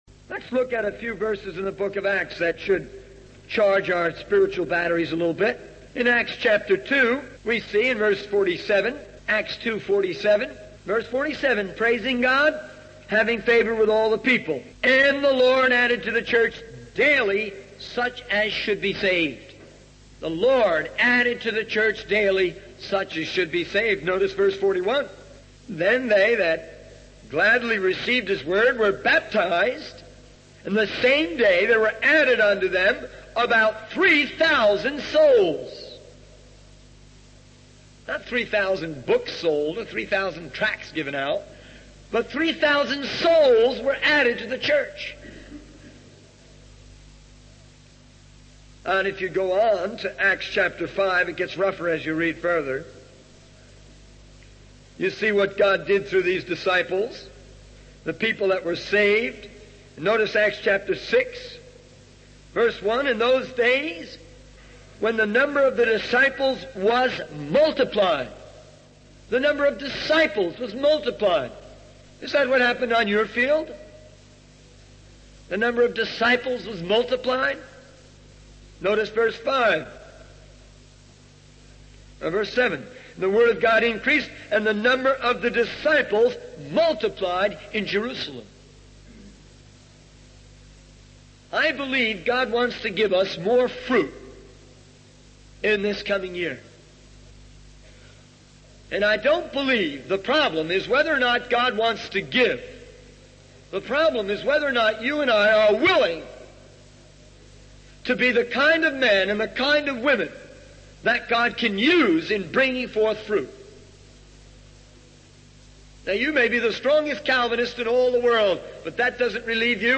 In this sermon, the speaker emphasizes the importance of having a life and fire for God that leads to winning souls.